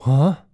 Male_Grunt_Curious_01.wav